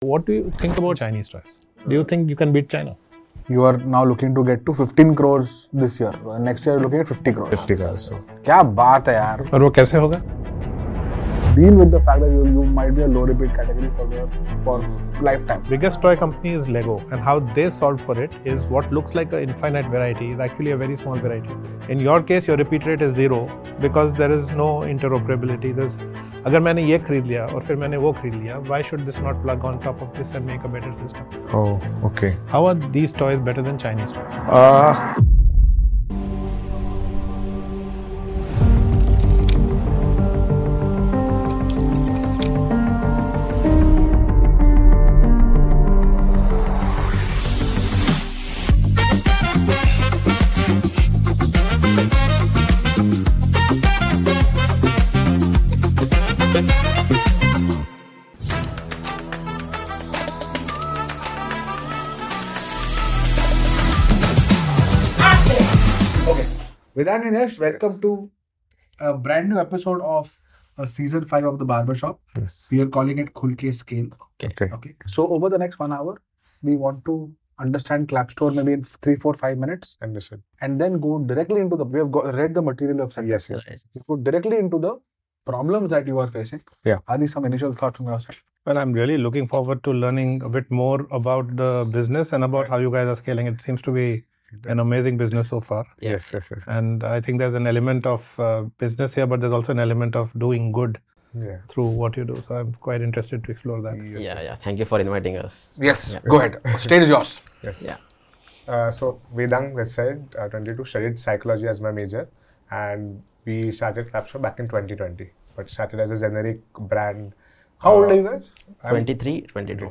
for a sharp conversation on building a fast-growing toy brand focused on affordable, mass-market products for Indian families.